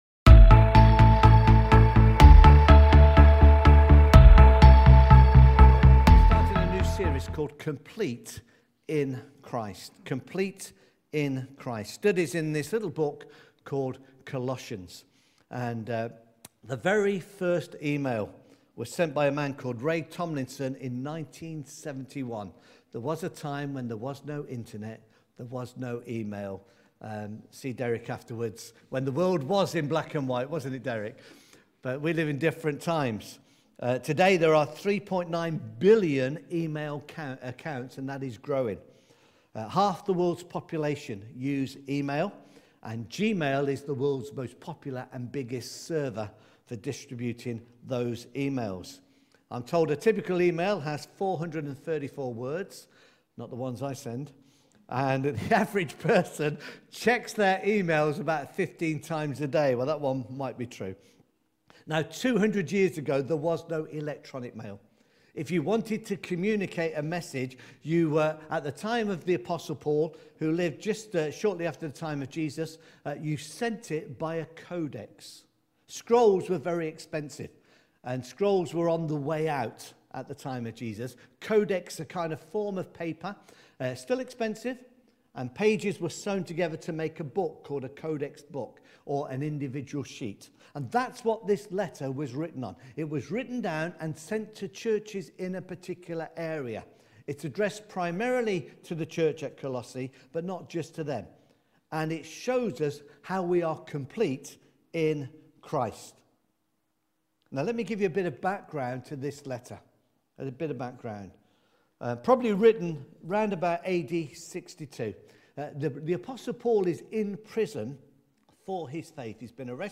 Colossians chapter 1 verses 1-14 – sermon